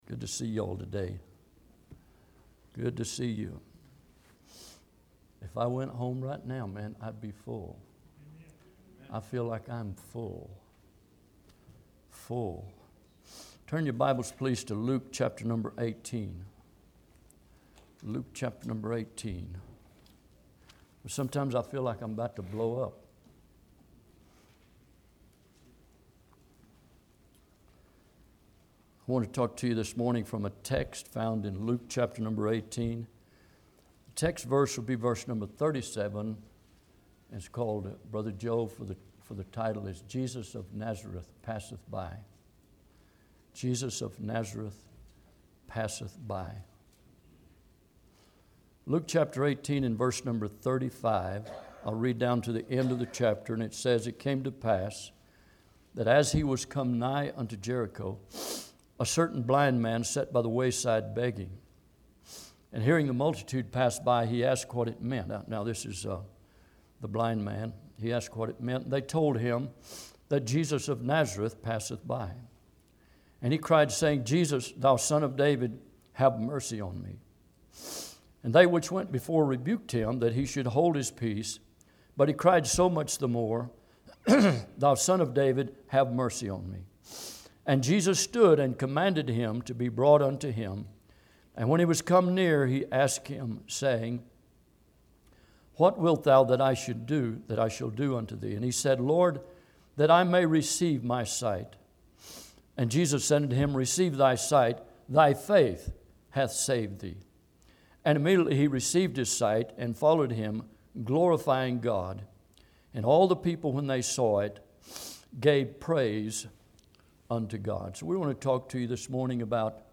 Bible Text: Luke 18:37 | Preacher